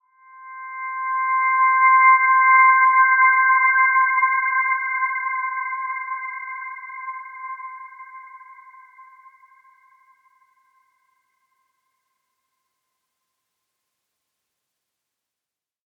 Dreamy-Fifths-C6-p.wav